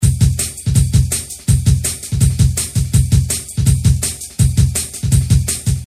Drum N Bass 11
描述：50个鼓 贝斯循环。
Tag: 165 bpm Drum And Bass Loops Drum Loops 1.00 MB wav Key : Unknown